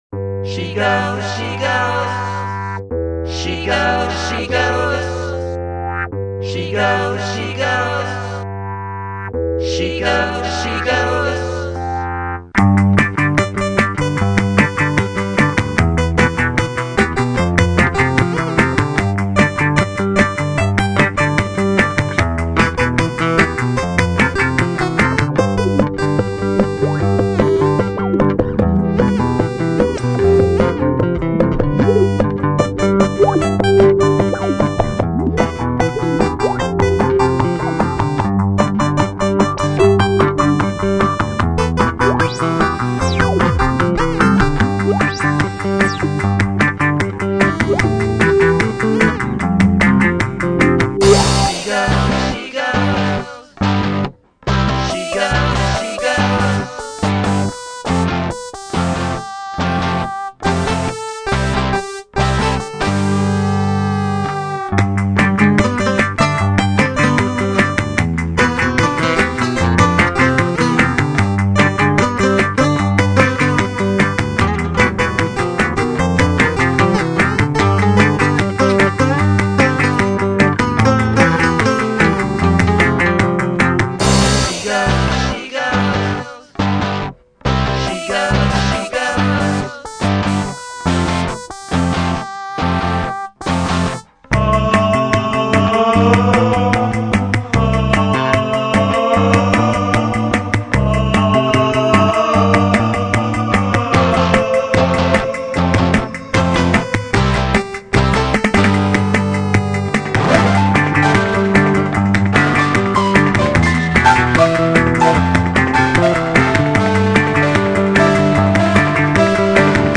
she goes - remix what
living room
sitar, e&p sing choir
piano